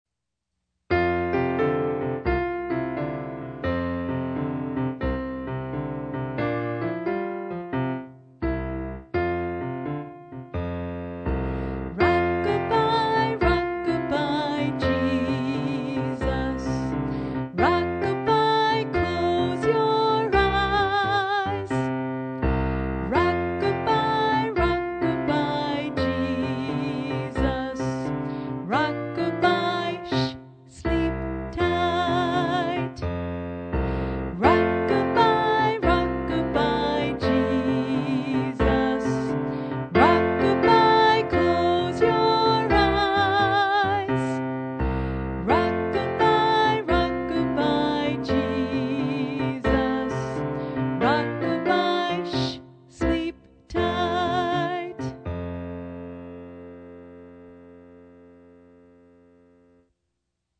Rock-a-bye-Jesus-Someone-Vocal.mp3